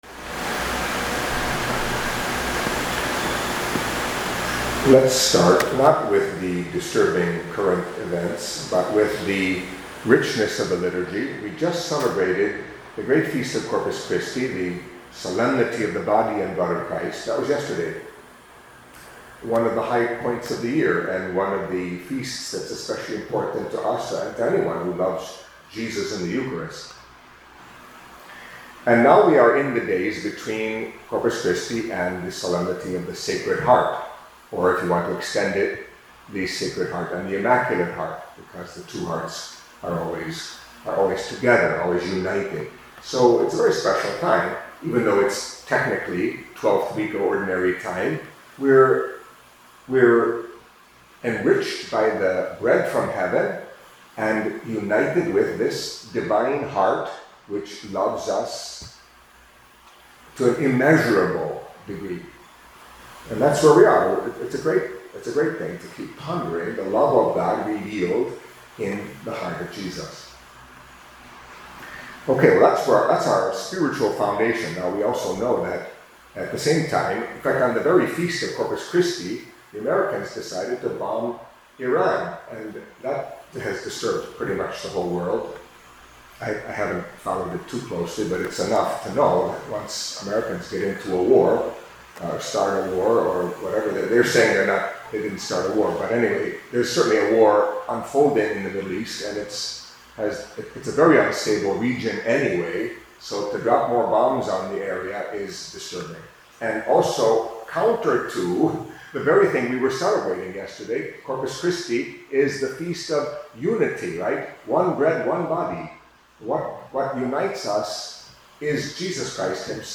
Catholic Mass homily for Monday of the Twelfth Week in Ordinary Time